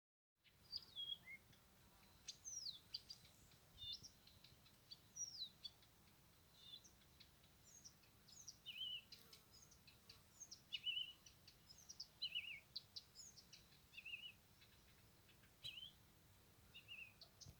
Blyth’s Reed Warbler, Acrocephalus dumetorum
Notes /Dzied